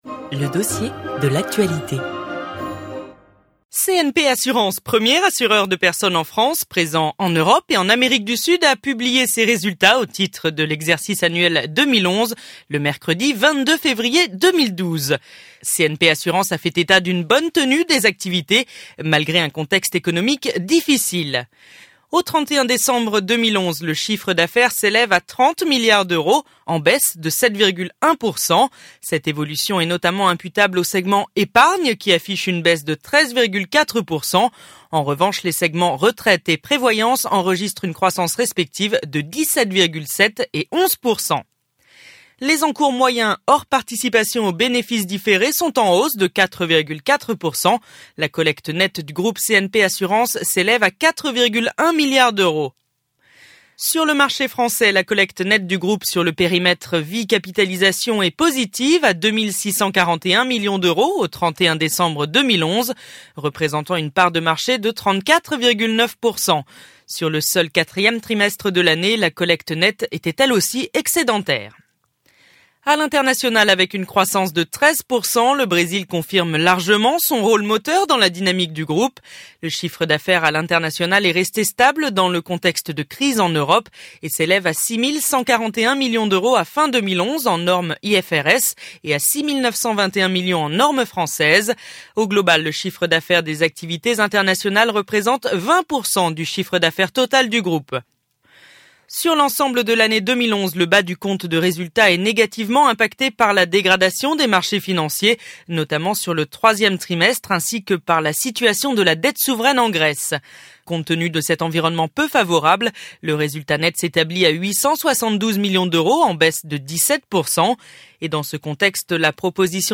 Résultats 2011 (conférence audio)